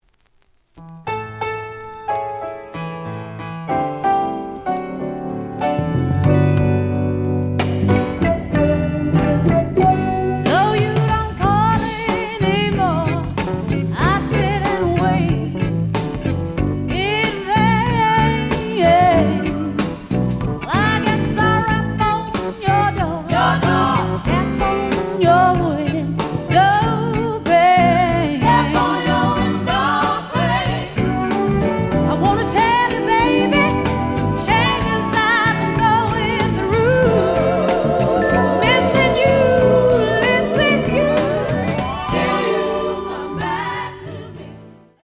試聴は実際のレコードから録音しています。
The audio sample is recorded from the actual item.
●Genre: Soul, 70's Soul